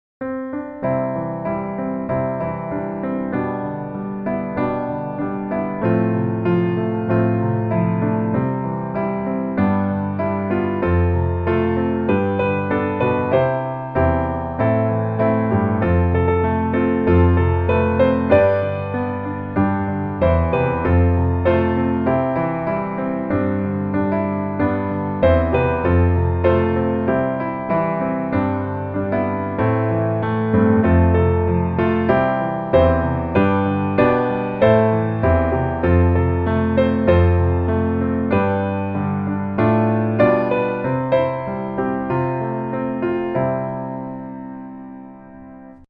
C Major